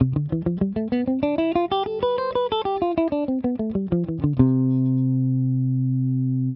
les modes naturels débutants guitare
Do Mi Fa Sol La Si
Mode Ionien à partir de Do : Tonique à la 8ème case
1-Do_Ionien.wav